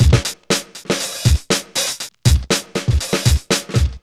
DISCOBEAT120.wav